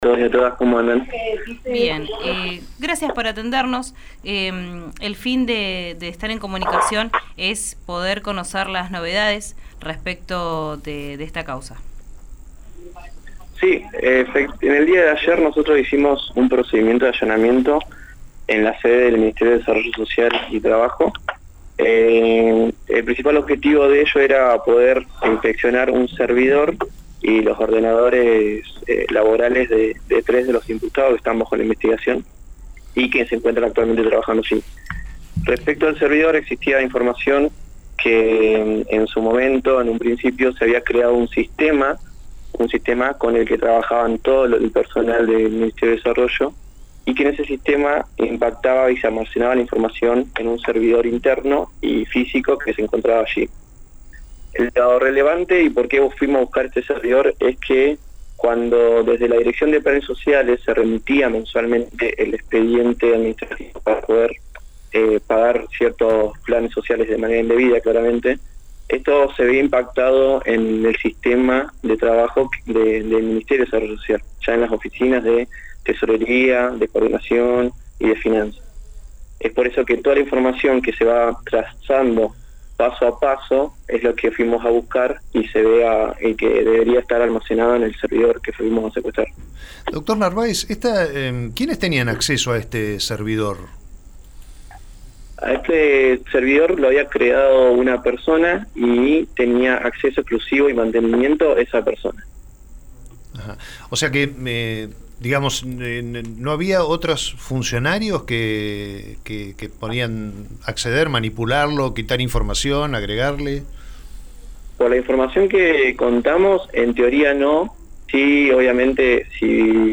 En diálogo con RÍO NEGRO RADIO, el fiscal Narváez explicó algunos detalles del allanamiento realizado desde la mañana del jueves en Planas y Anaya, sede del Ministerio que está bajo investigación desde julio del año pasado.
EscuChá al fiscal de Delitos Complejos, Juan Manuel Narváez, en RÍO NEGRO RADIO: